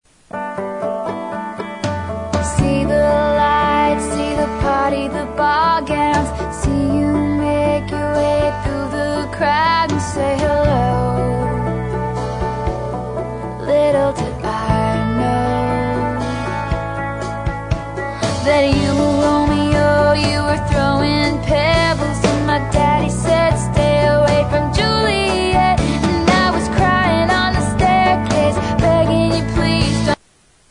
• Country Ringtones